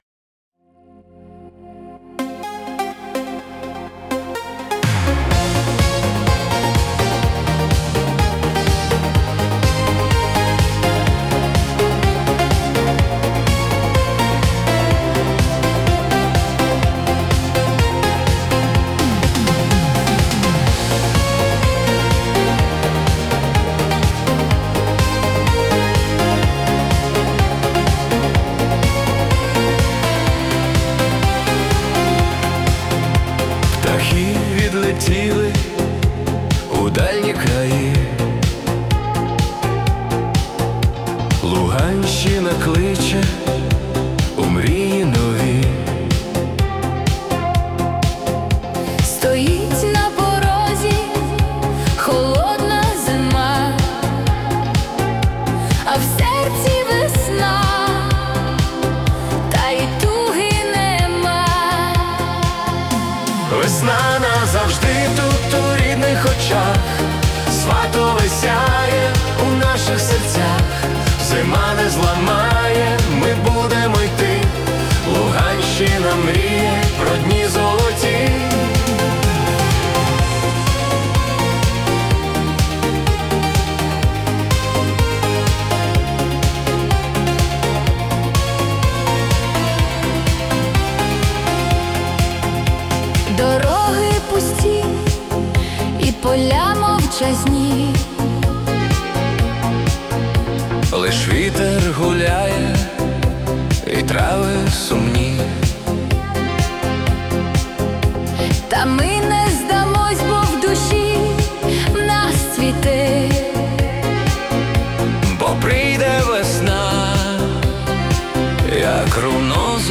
🎵 Жанр: Italo Disco / Winter Pop